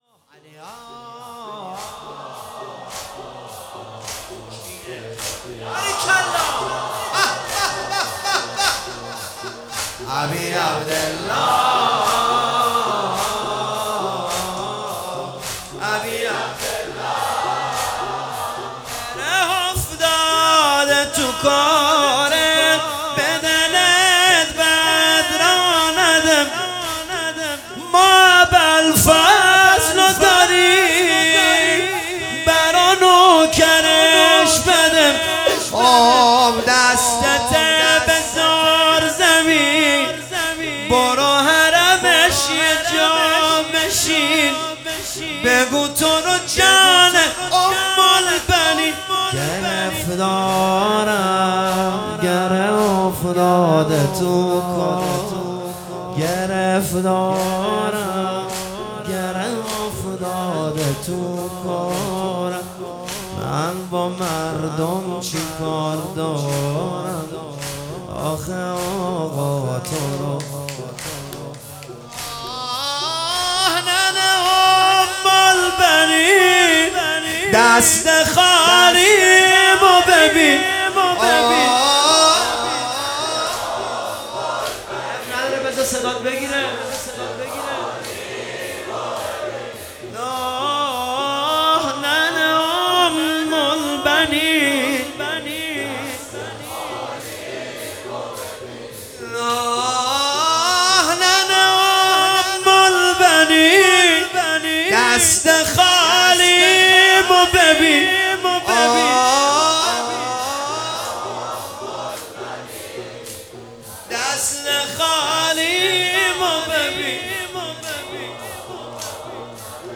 دانلود فایل صوتی مداحی زمینه حضرت ابالفضل عباس
جلسه هفتگی اردیبهشت 1404